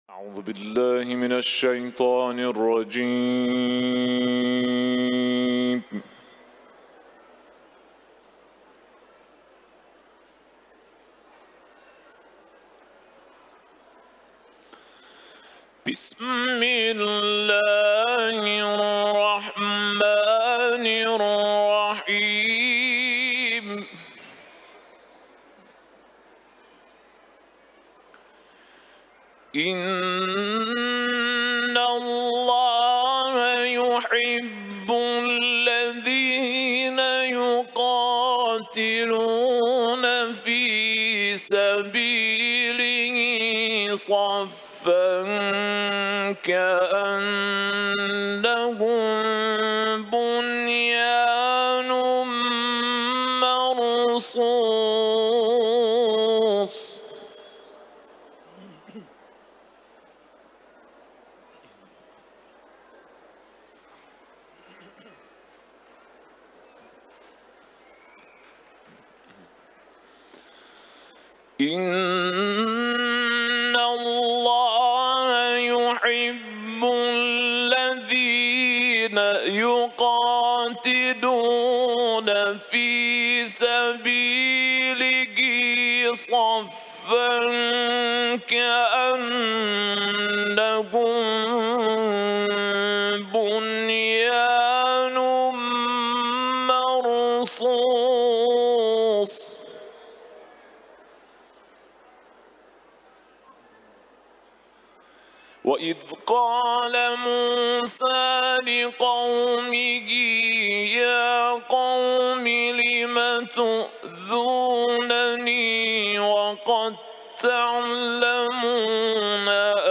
Audio | Rezitation
aus dem Schrein in Mashhad gewidmet.